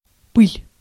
Ääntäminen
Ääntäminen France: IPA: [pu.sjɛʁ] Haettu sana löytyi näillä lähdekielillä: ranska Käännös Ääninäyte Substantiivit 1. пыль {m} (pyl) Suku: f .